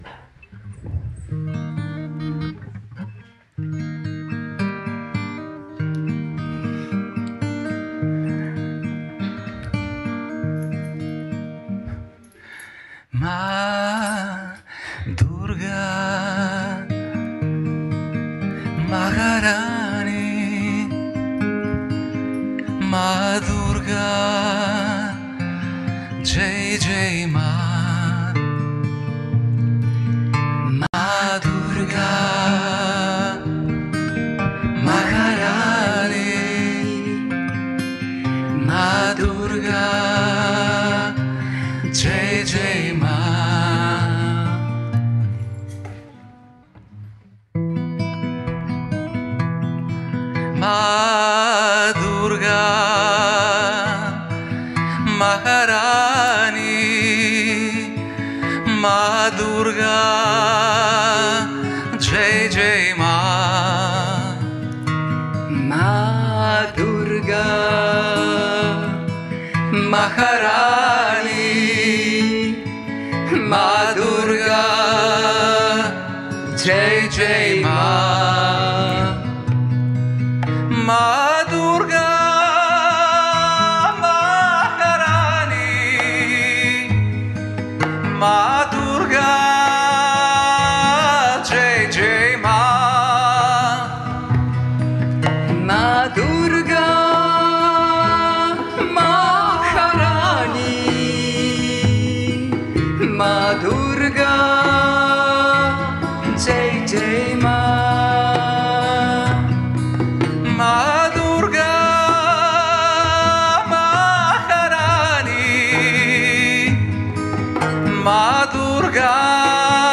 in a Saturday evening Satsang
because this Bhajan is not to be found in the Yoga Vidya Kirtan Booklet Blog.